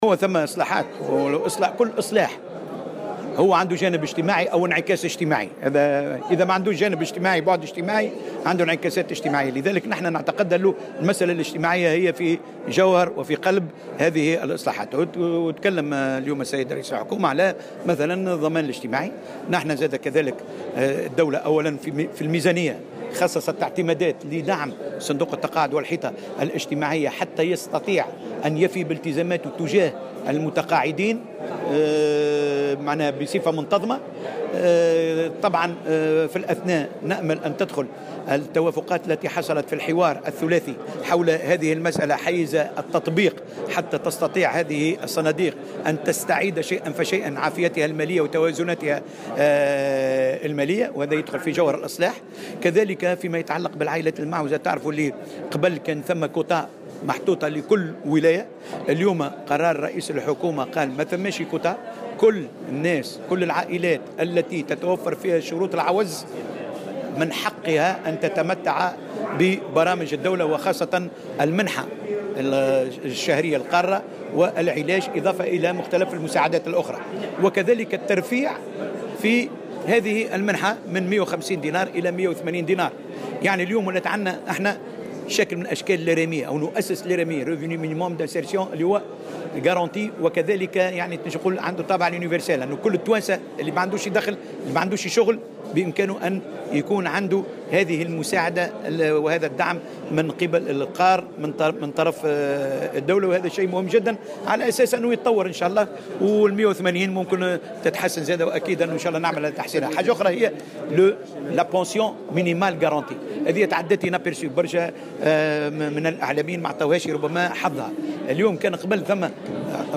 قال وزير الشؤون الاجتماعية، محمد الطرابلسي إن الإصلاحات والإجراءات التي تضمنها مشروع قانون المالية الجديد، سيكون لها انعكاسات اجتماعية.
وأضاف اليوم على هامش الندوة الوطنية حول التوجهات الاقتصادية والاجتماعية لمشروع قانون المالية لسنة 2019" أنه تم التنصيص على تمتيع كل العائلات المعوزة ومحدودة الدخل في تونس بالعلاج المجاني و الترفيع في المنحة الشهرية المسندة لهم من 150 د إلى 180 د، إضافة إلى جملة من المساعدات الأخرى وإقرار جراية دنيا للتقاعد لا تقل عن 180 دينارا .كما تحدث أيضا عن تخصيص اعتمادات إضافية لدعم صندوق التقاعد والحيطة الاجتماعية حتى يستطيع الايفاء بالتزاماته تجاه المتقاعدين.